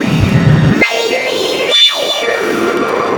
WINDTEXTUR-R.wav